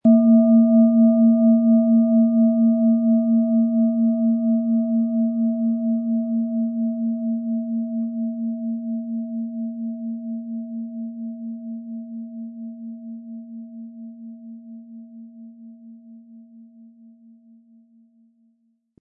• Tiefster Ton: Mond
• Höchster Ton: Mond
Wie klingt diese Planetenton-Klangschale Hopi-Herzton?
PlanetentöneHopi Herzton & Mond
MaterialBronze